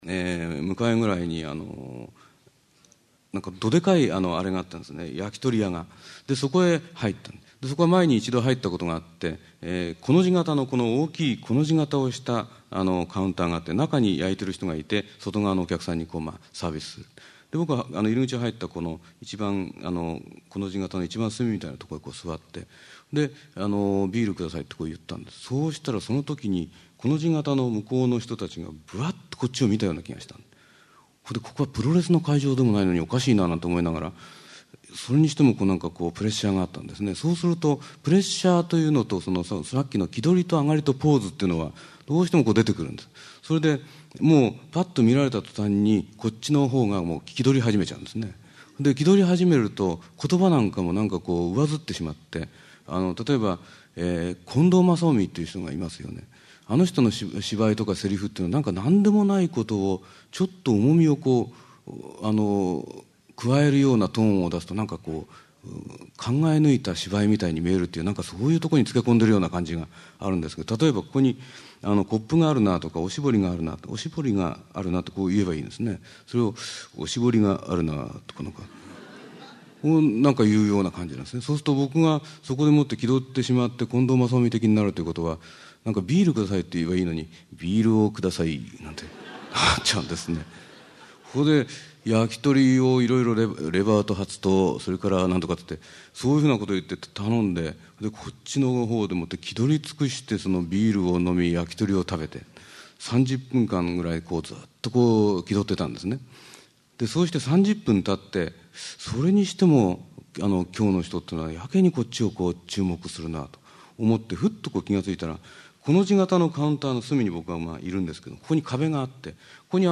名だたる文筆家が登場する、文藝春秋の文化講演会。
（1989年4月22日 福井市フェニックスプラザ 菊池寛生誕百周年記念講演会より）